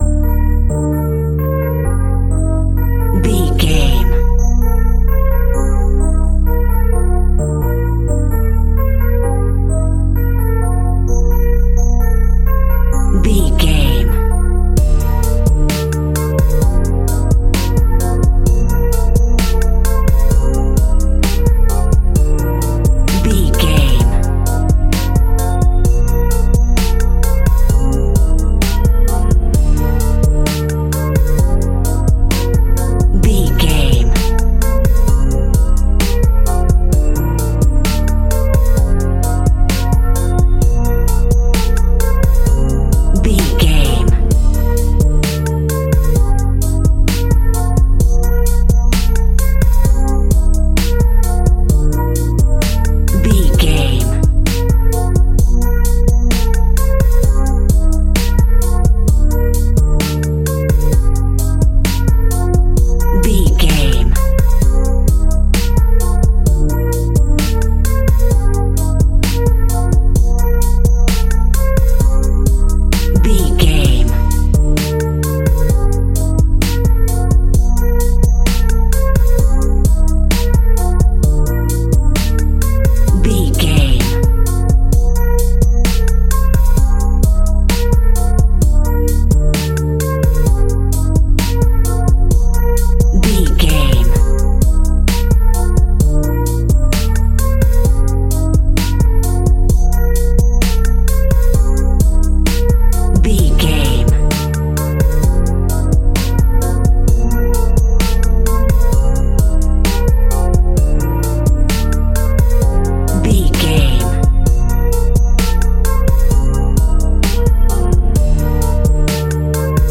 Chillax Rap Background Instrumental Beat.
Ionian/Major
B♭
hip hop
chilled
laid back
groove
hip hop drums
hip hop synths
piano
hip hop pads